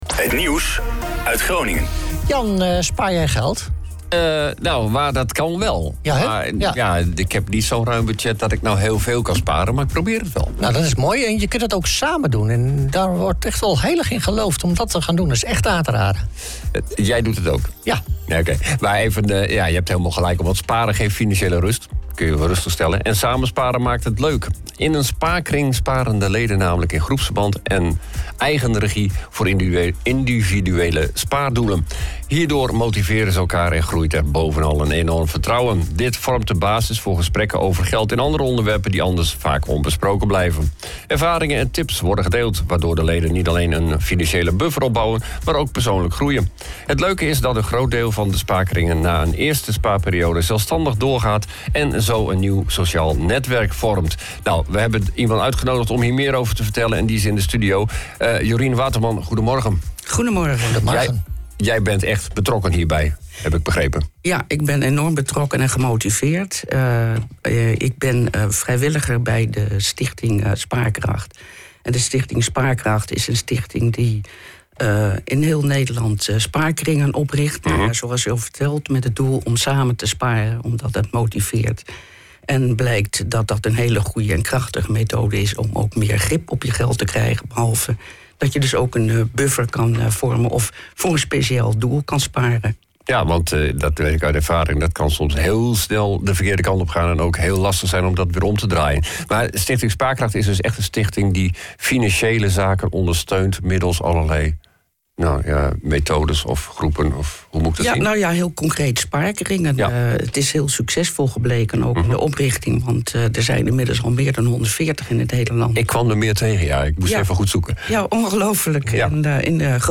De OOG Ochtendshow is een vrolijke radioshow met het lokale nieuws, de beste muziek en natuurlijk het weer.
Hier vind je alle interviews van de OOG Ochtendshow.